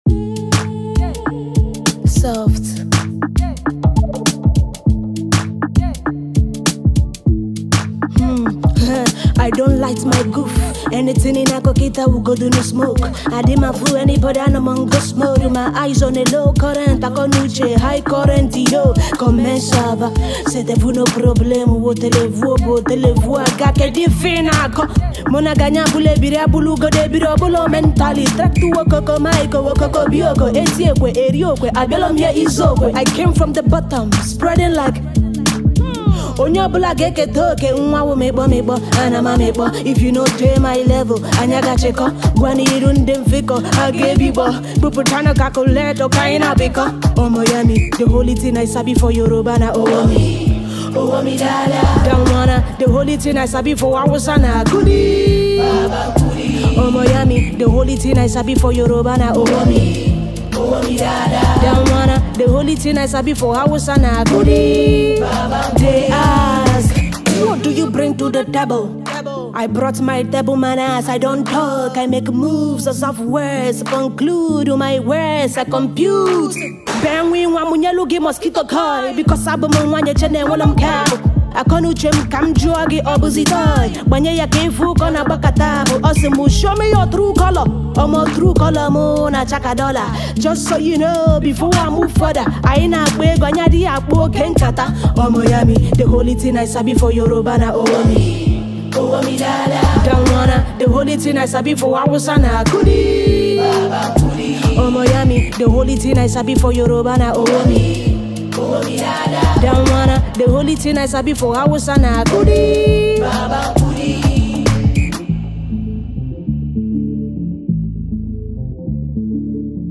indigenous Igbo Rapper